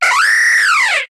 Cri de Brutalibré dans Pokémon HOME.